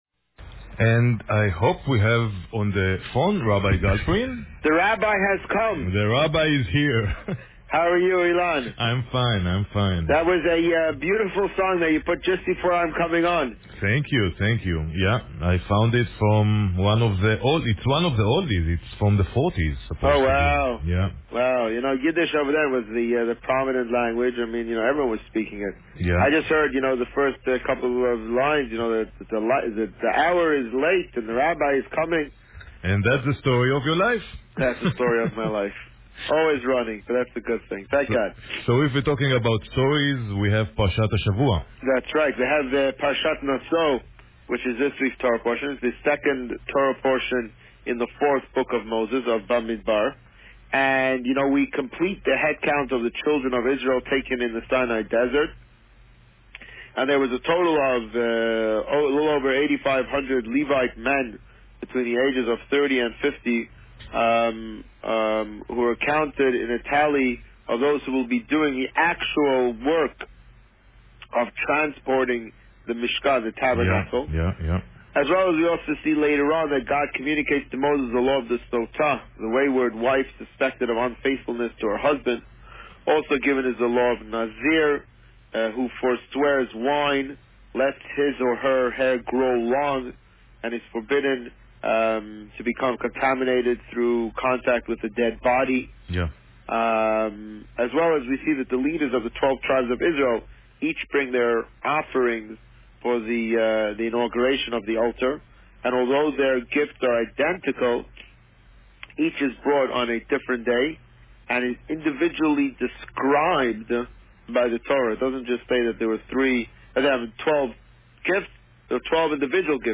This week, the Rabbi spoke about Parsha Naso. Listen to the interview here.